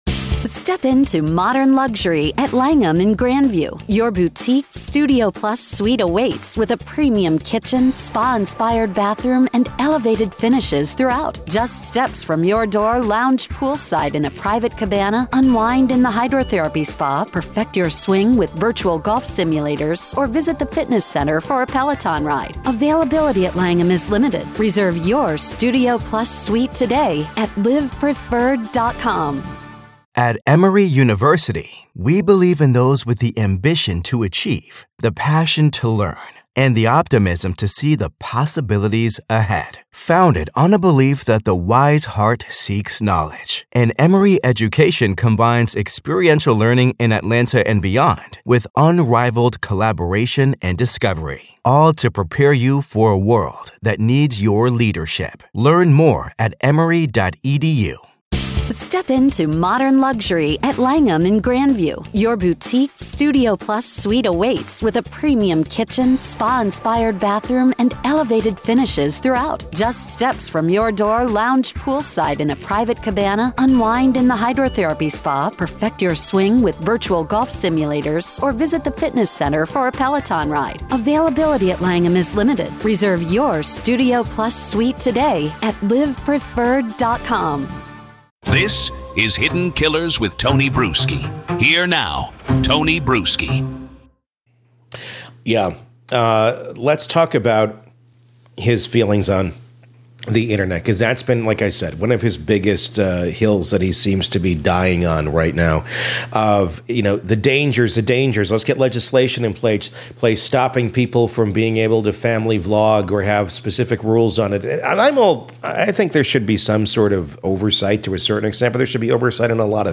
psychotherapist